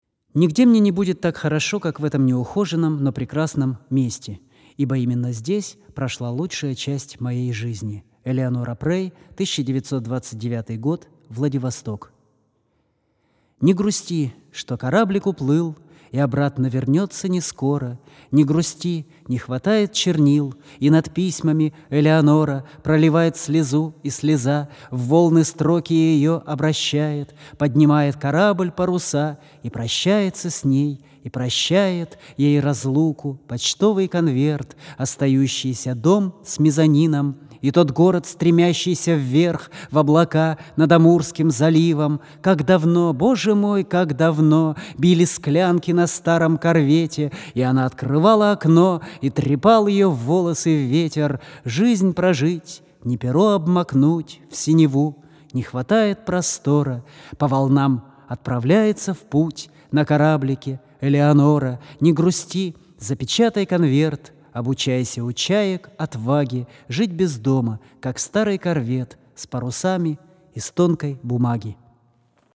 под гитару